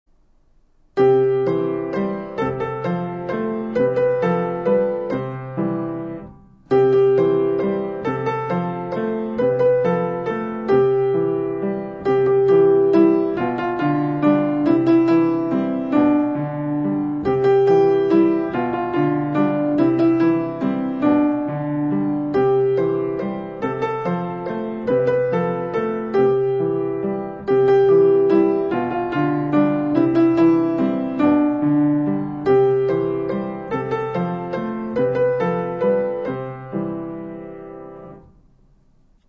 ピアノ演奏